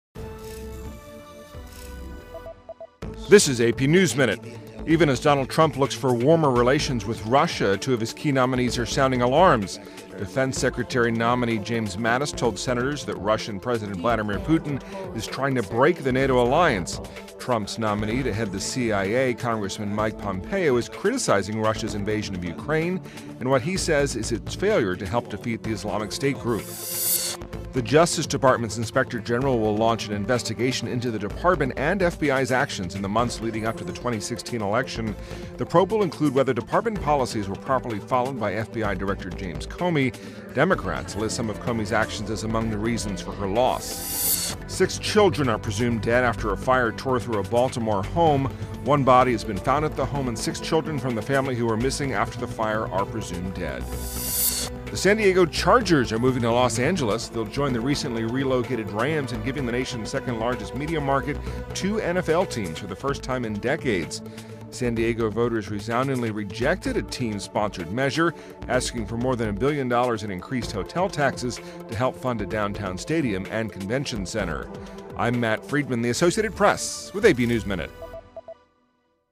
News
美语听力练习素材:特朗普内阁提名人要求警惕俄罗斯